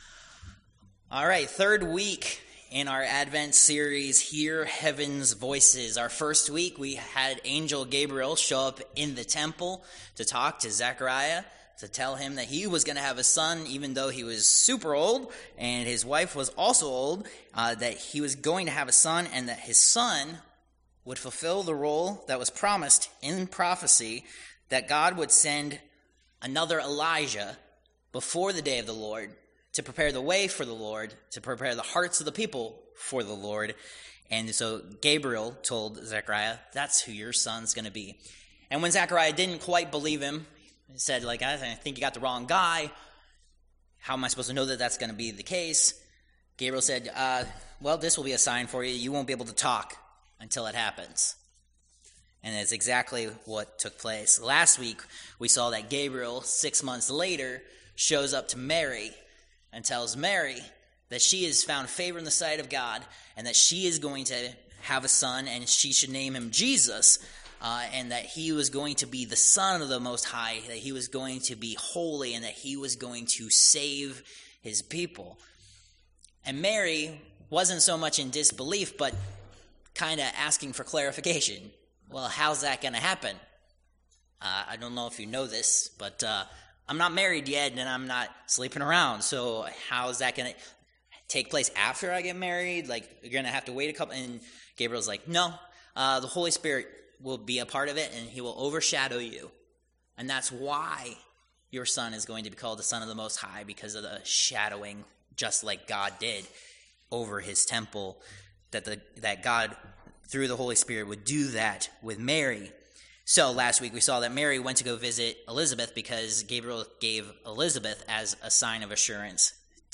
Passage: Matt. 1:18-25 Service Type: Worship Service